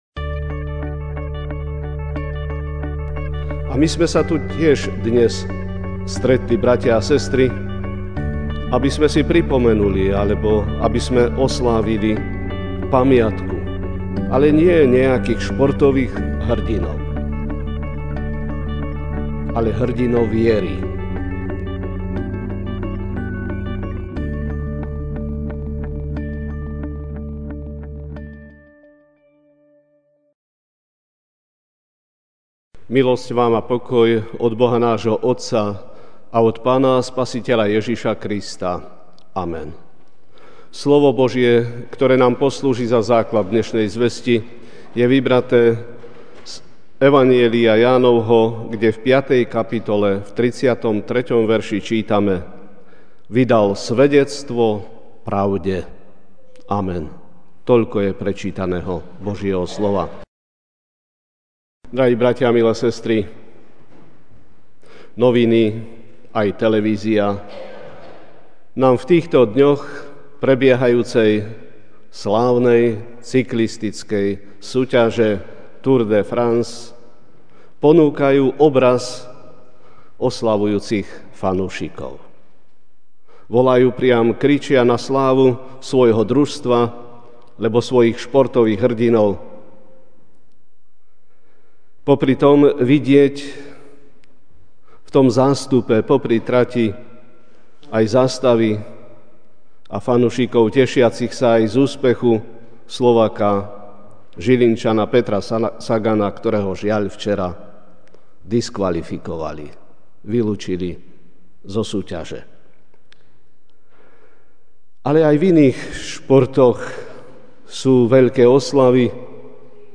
júl 05, 2017 Svedectvo o pravde MP3 SUBSCRIBE on iTunes(Podcast) Notes Sermons in this Series Ranná kázeň: Svedectvo o pravde (Ján 5, 33) Vy ste poslali k Jánovi, a ten vydal svedectvo pravde; Ten istý Príprava, ktorá sa oplatí!